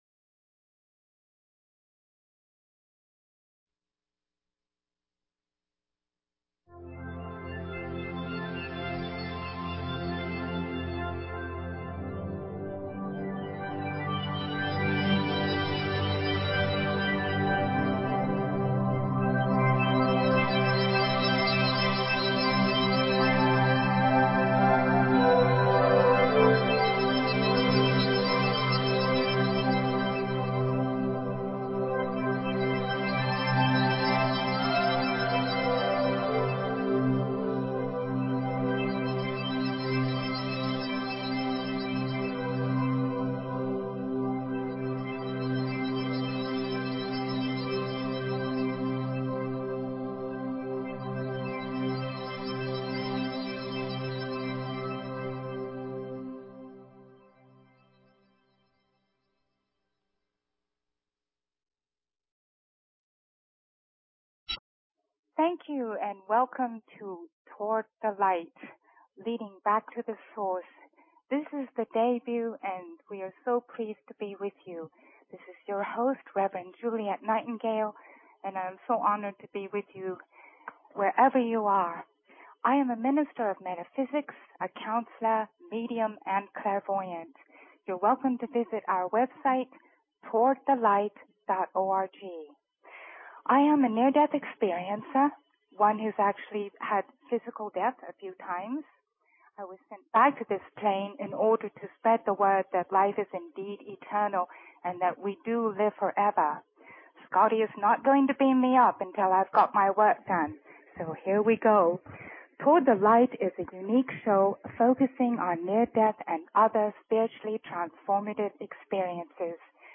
Talk Show Episode, Audio Podcast, Toward_The_Light and Courtesy of BBS Radio on , show guests , about , categorized as
From England, her accent immediately draws people in.